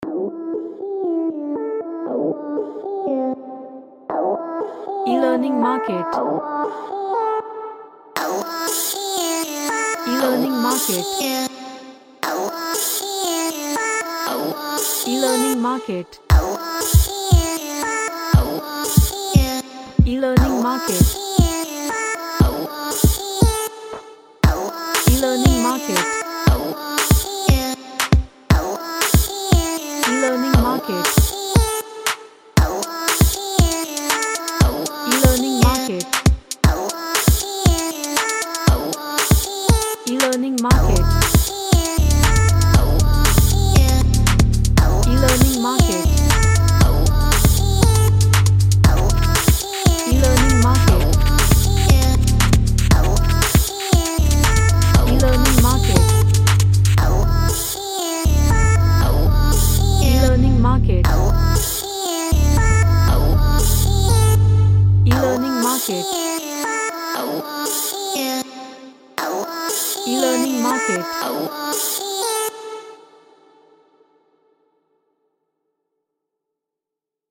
A chill pop track
Chill Out